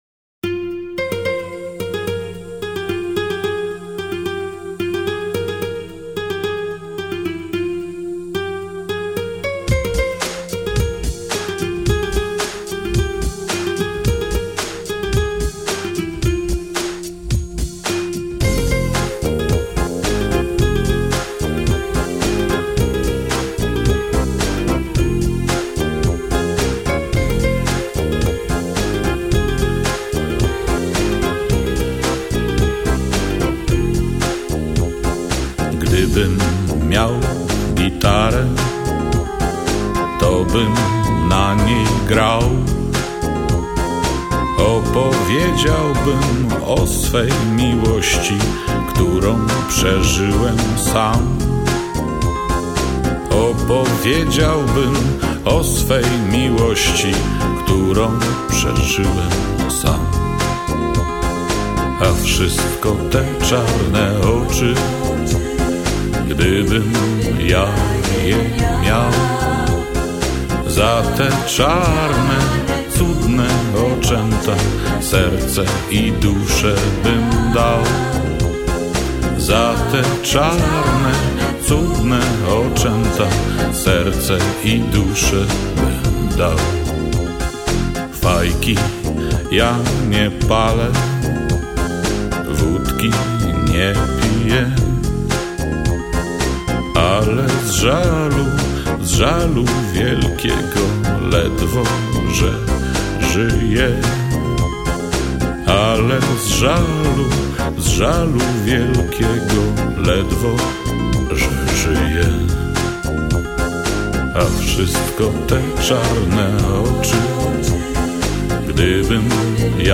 Стиль Восса Нова